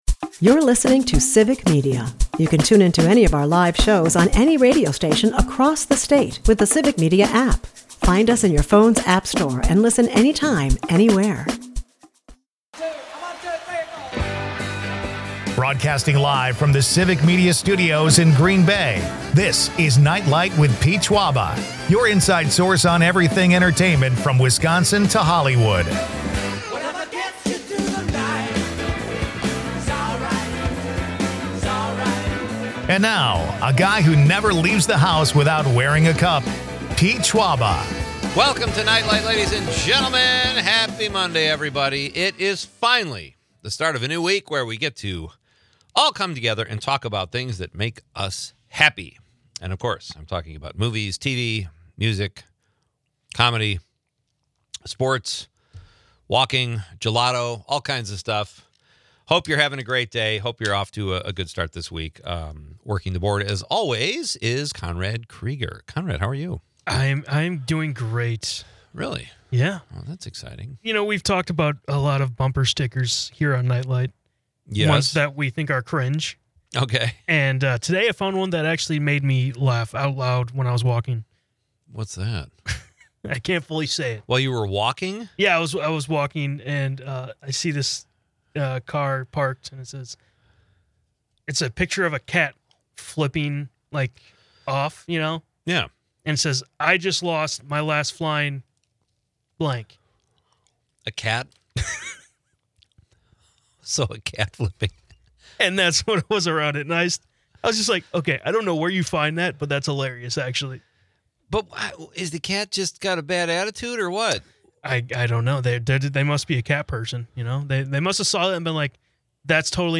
As National Laziness Day unfolds, listeners reveal their own lazy habits, sparking plenty of laughs.